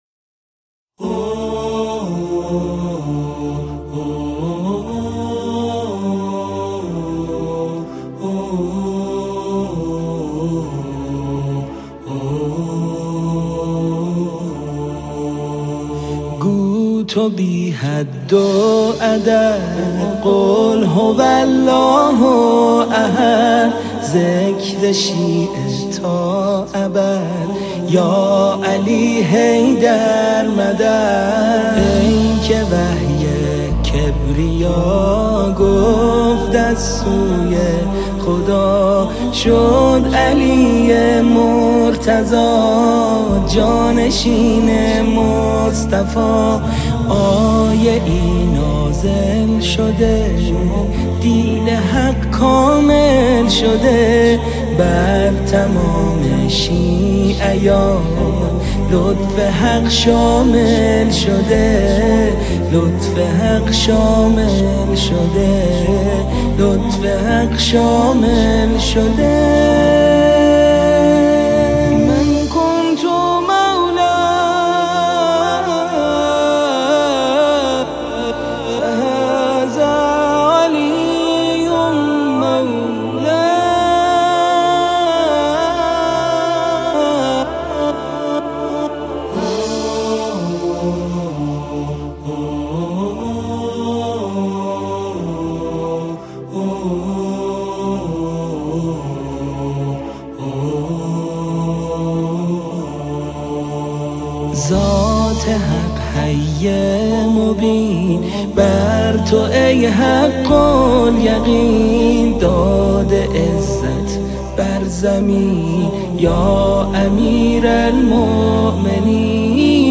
مولودی زیبا و دلنشین